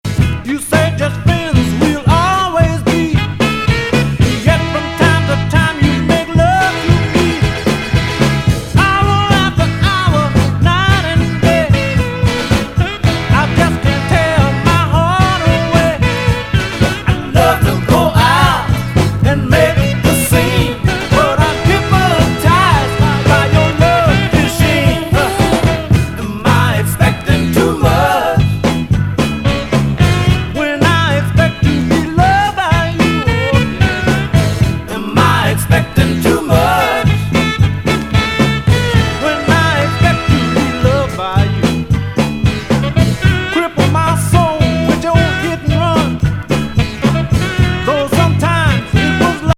南部の芳醇な香りがたちこめる傑作!